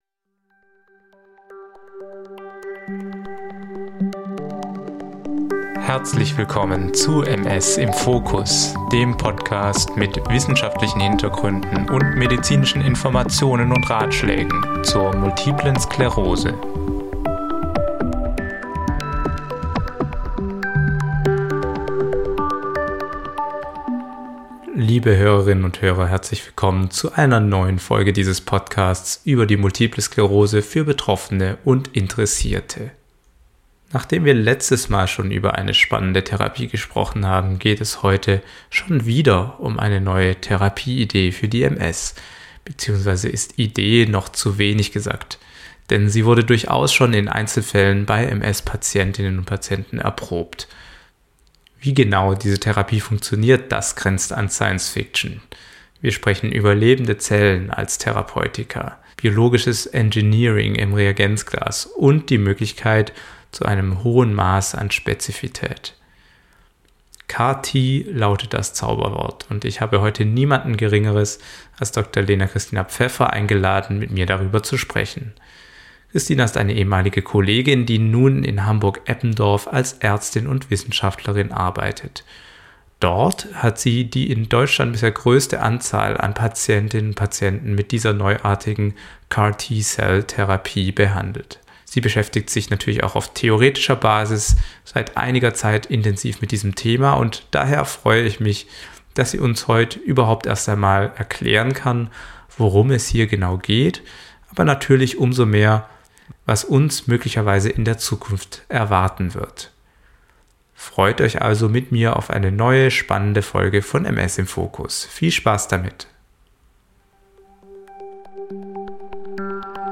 Sci-Fi oder Heilsbringer? CAR-T Zelltherapie in der MS - Interview